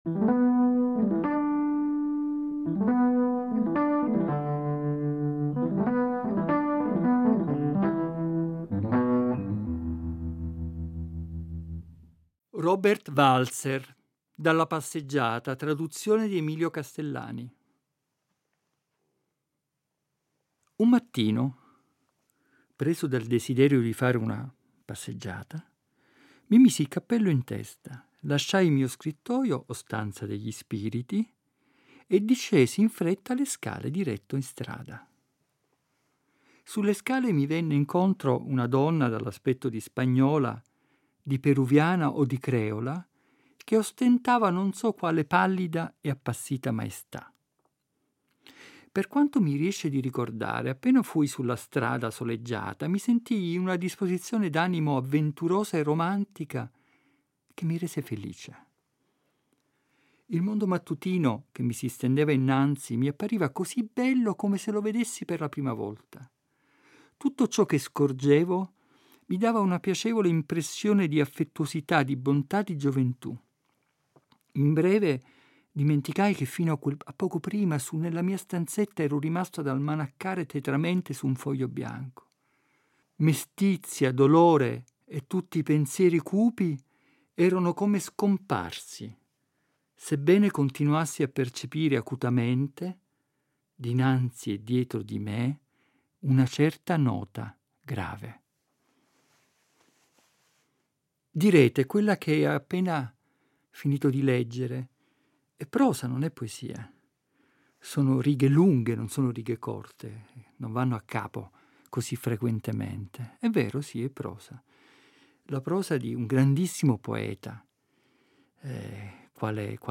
Ed è a partire da questo simbolo «delle equazioni casalinghe» che hanno caratterizzato il tempo del lockdown (una parte delle registrazioni è stata pensata e realizzata proprio fra le mura domestiche) che egli ci guida nella rigogliosa selva della parola poetica per «dare aria ai pensieri». La vita degli occhi quando si esce all’aria aperta e si svaga la mente: i paesaggi, gli incontri, gli scambi di sguardi con gli altri, la scoperta che “l’amicizia è la prosa dell’amore”.